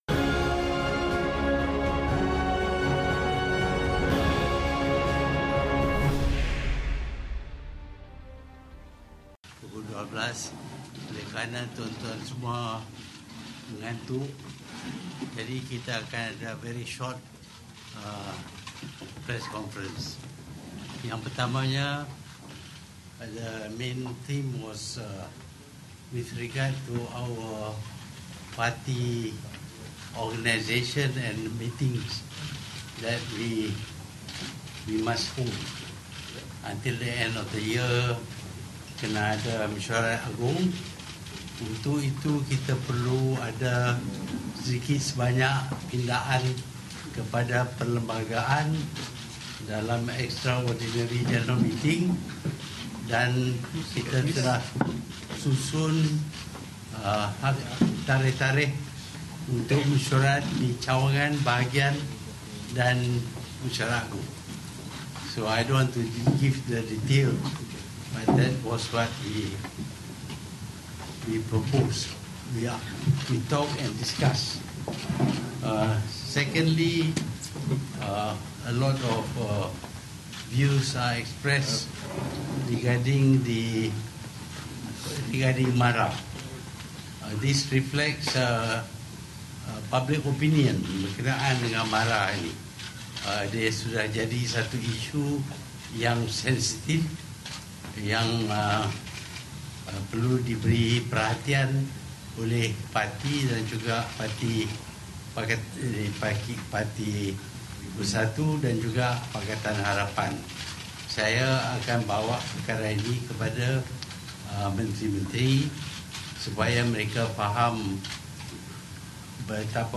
Sidang media PPBM (10 Ogos 2018)
Sidang media oleh Pengerusi PPBM, Tun Dr Mahathir Mohamad lewat malam tadi memperincikan beberapa perkara termasuk keinginan rakyat dan pemimpin Sarawak mahu menyertai parti itu.